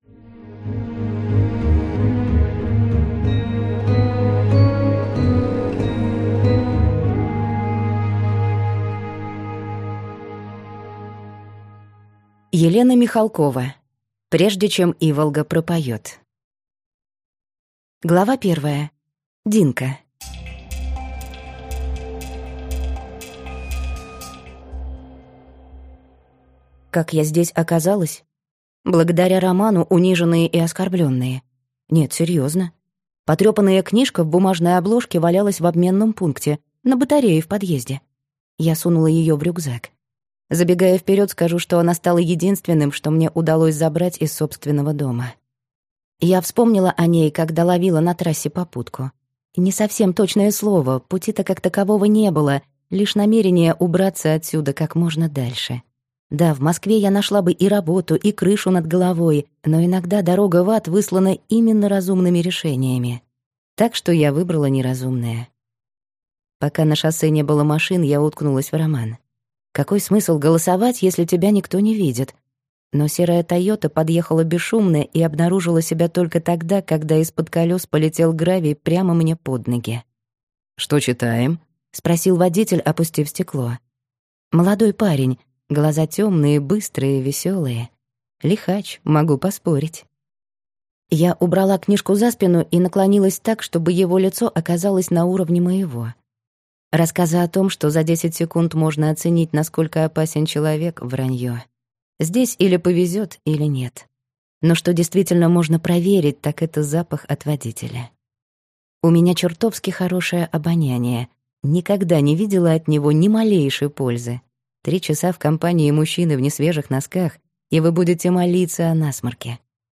Аудиокнига Прежде чем иволга пропоет - купить, скачать и слушать онлайн | КнигоПоиск